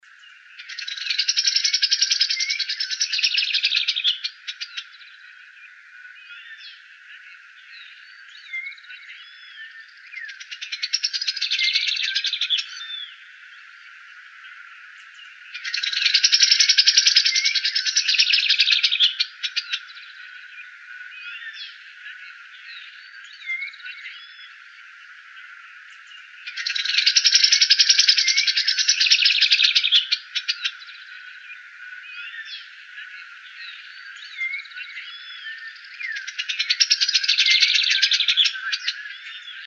Shiny Cowbird (Molothrus bonariensis)
Sex: Female
Life Stage: Adult
Location or protected area: Reserva Ecológica Costanera Sur (RECS)
Condition: Wild
Certainty: Recorded vocal
Recs.Tordo-renegrido-hembra.mp3